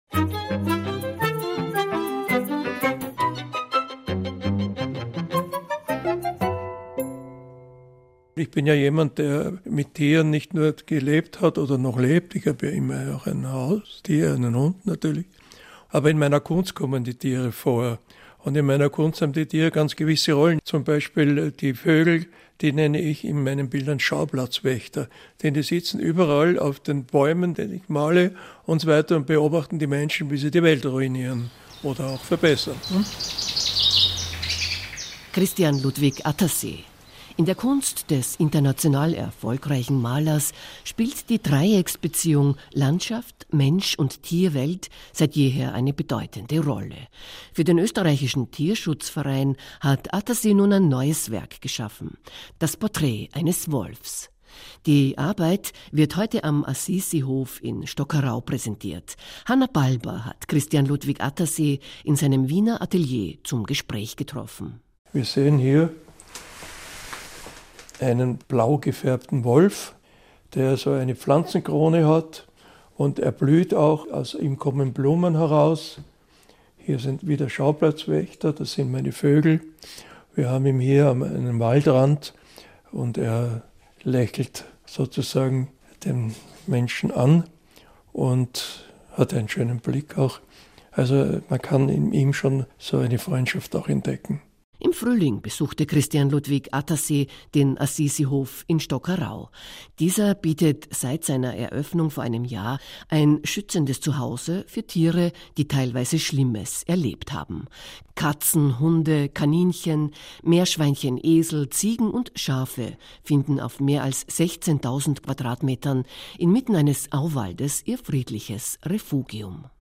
Ö1-Interview: Der Maler und der Wolf